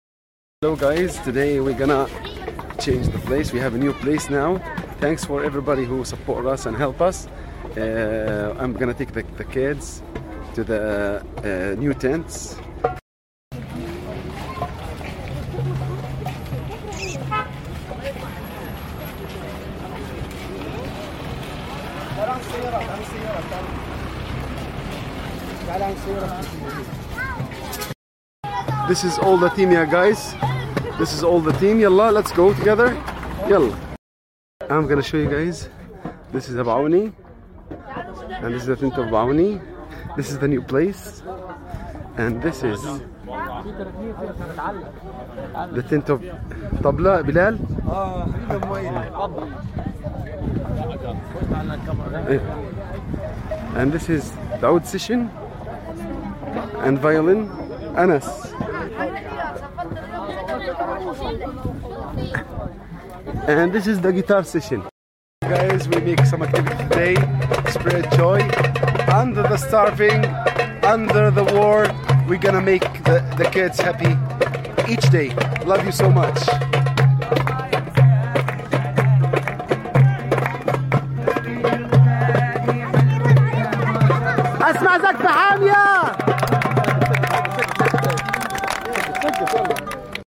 I sing not in spite of it, but because of it.